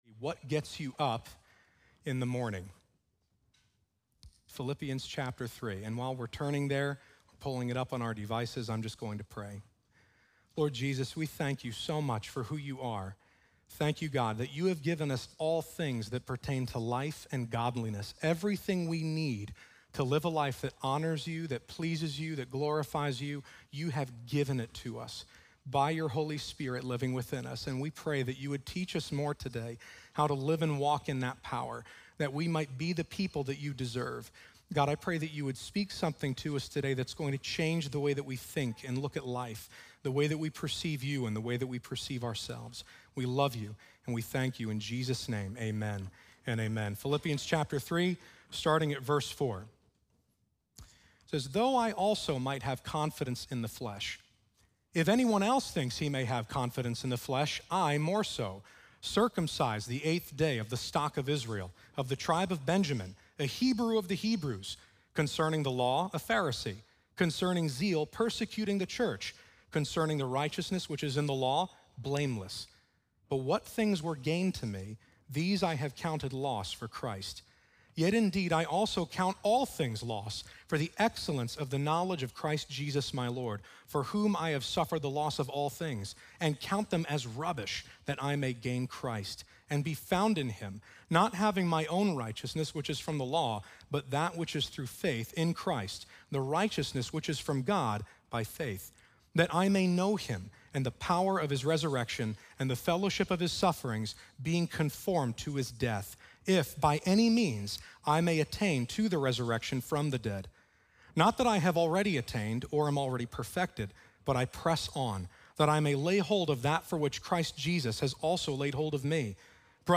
What Gets You Up In The Morning | Times Square Church Sermons